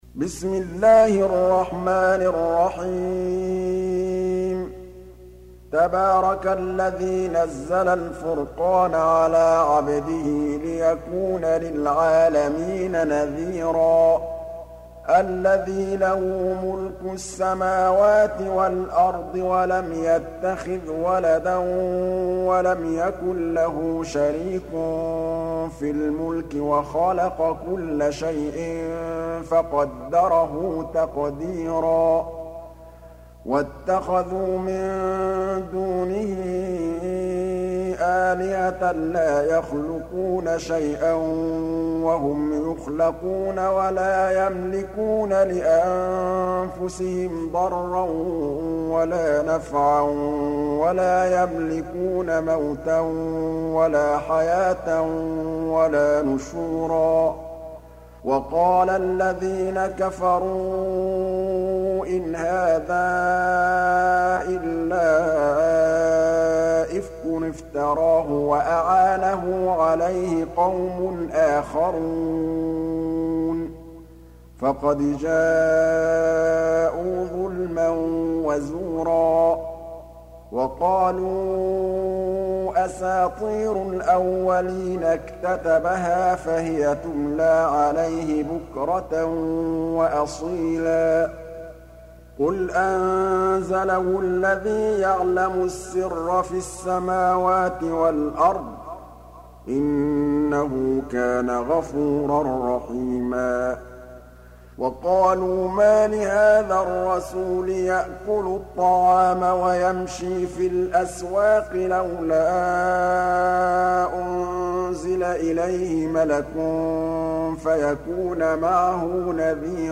25. Surah Al-Furq�n سورة الفرقان Audio Quran Tarteel Recitation
Surah Sequence تتابع السورة Download Surah حمّل السورة Reciting Murattalah Audio for 25. Surah Al-Furq�n سورة الفرقان N.B *Surah Includes Al-Basmalah Reciters Sequents تتابع التلاوات Reciters Repeats تكرار التلاوات